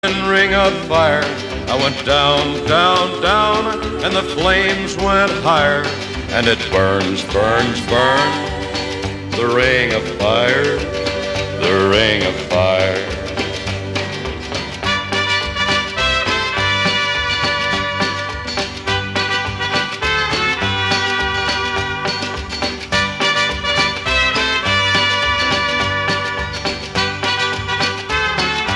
Gattung: Country-Klassiker
Besetzung: Blasorchester
Ebenfalls ein schwungvoller Country-Klassiker